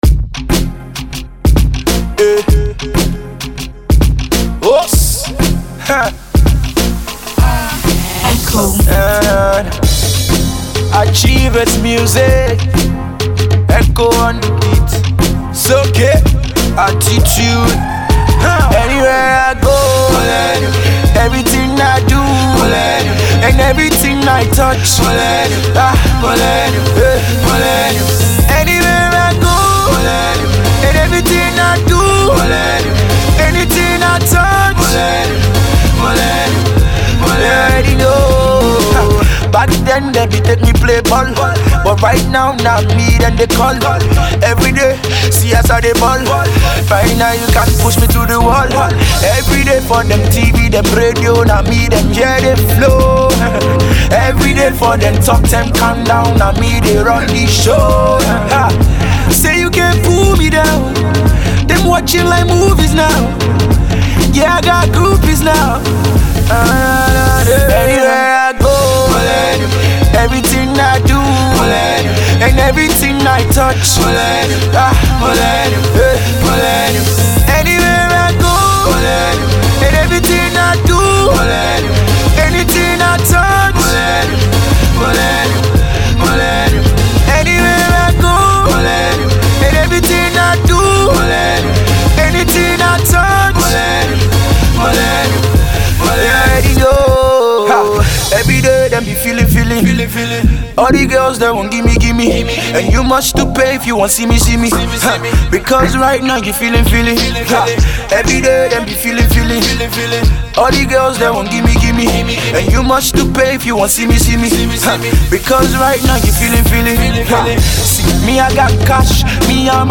which boasts of a refreshing blend of hip-hop and Afrobeats.